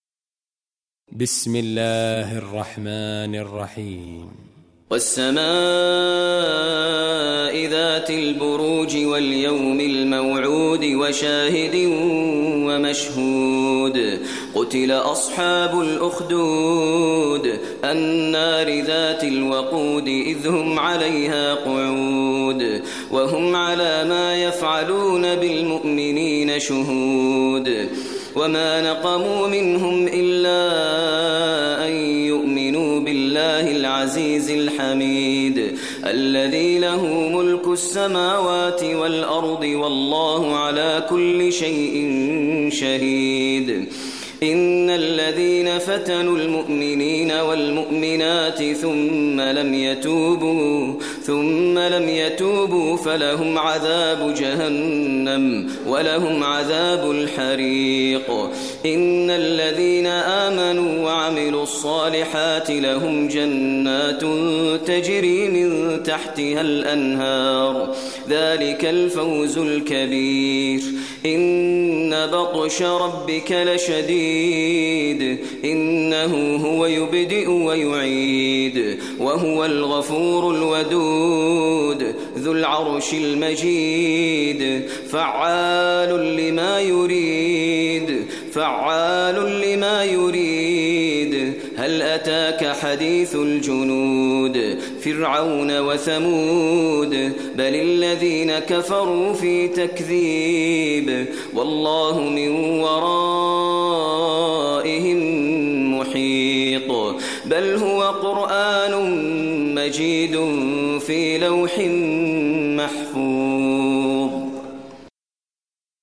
Surah Buruj Recitation by Maher al Mueaqly
Surah Buruj, listen online mp3 tilawat / recitation in Arabic recited by Imam e Kaaba Sheikh Maher al Mueaqly.